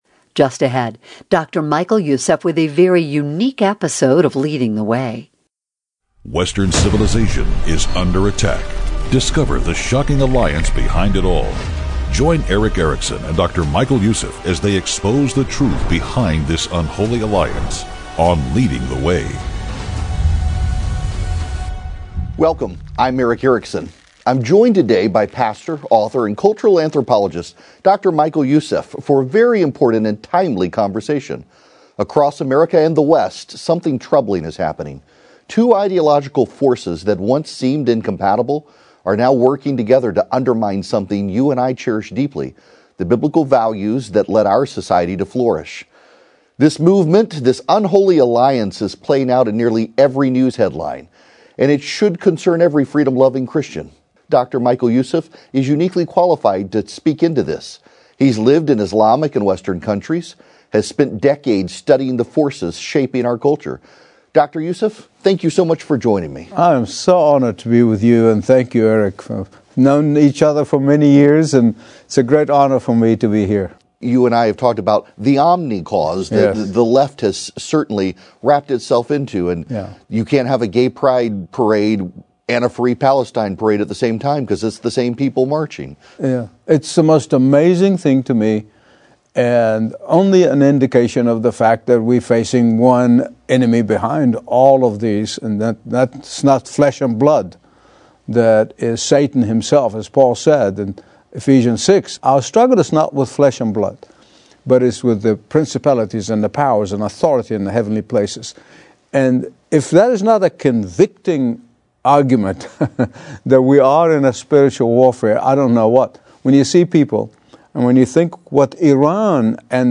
Listen to An Unholy Alliance Radio Special